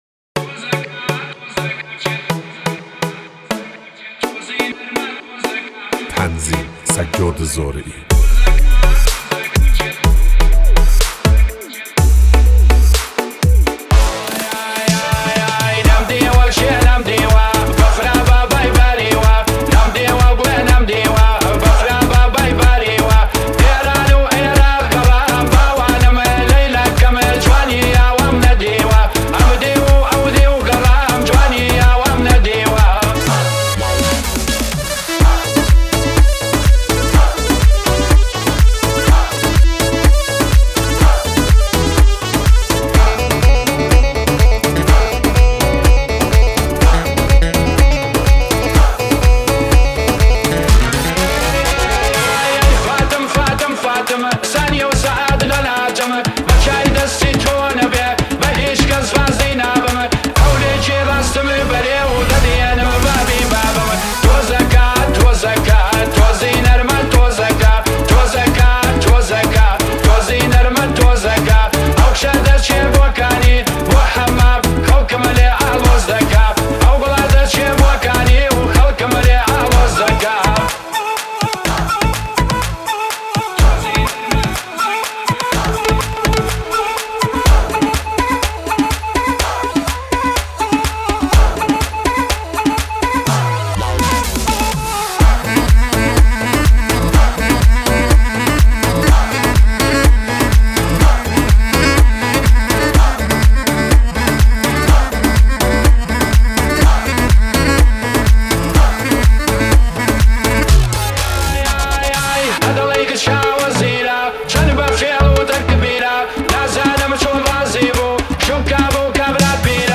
ساخته شده با هوش مصنوعی
537 بازدید ۱۴ بهمن ۱۴۰۲ هوش مصنوعی , ریمیکس کردی